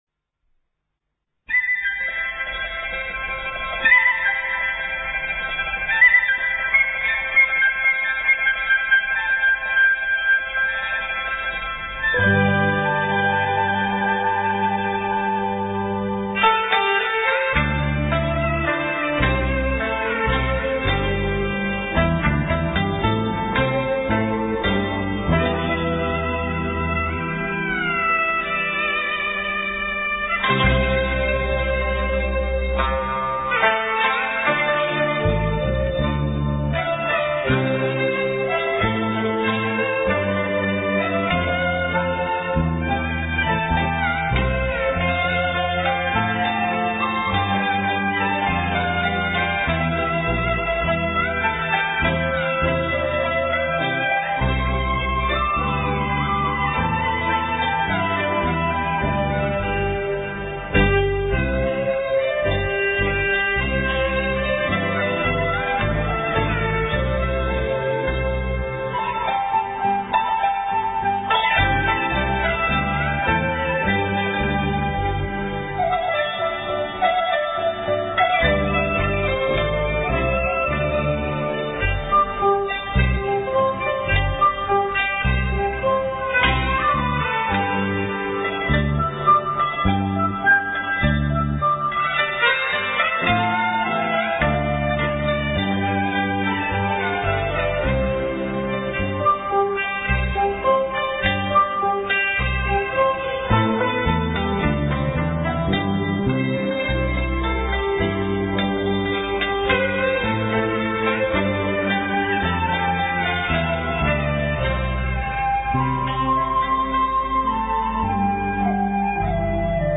廣東音樂柳浪聞鶯 Cantonese Music Birds Returning to the Forest
在曲首加上了前奏，用笛子奏出引子，有鳥鳴之意。樂曲以抒情的旋律和輕盈跳動的鶯啼模擬，描繪了柳林隨風搖擺、群鳥枝頭歌唱的自然景色。